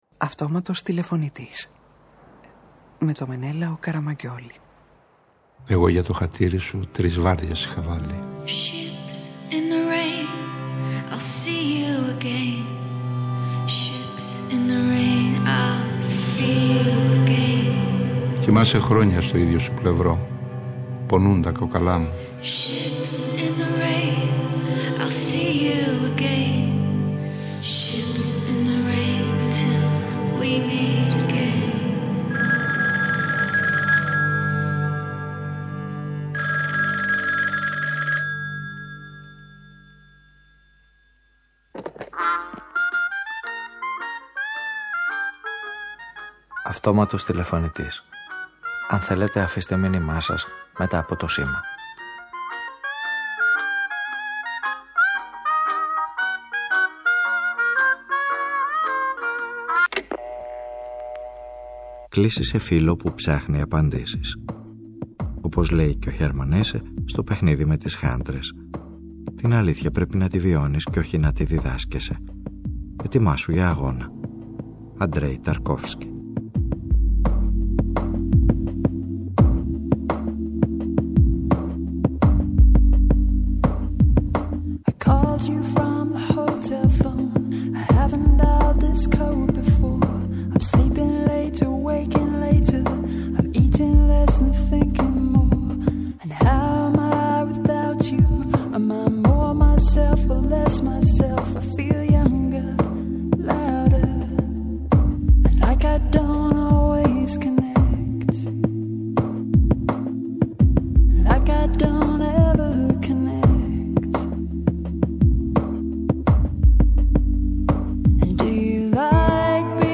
ΔΕΝ ΕΡΕΥΝΩ-ΒΡΙΣΚΩ“Την αλήθεια πρέπει να τη βιώνεις κι όχι να τη διδάσκεσαι” είναι η προτροπή του Έρμαν Έσσε στους ήρωες αυτής της ραδιοφωνικής ταινίας και μαζί με τον Αντρέι Ταρκόφσκι και τον Νίτσε (στις τελευταίες επιστολές του) δίνουν σαφείς απαντήσεις σε φίλους που αναρωτιώνται και “συνενόχους” που αμφιβάλλουν.